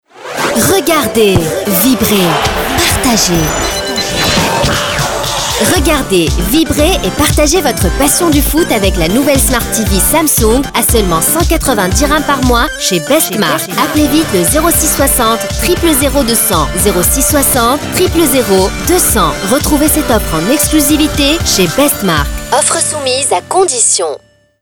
Radio - Voix énergique
spot-radio--voix-energique-._sources.mp3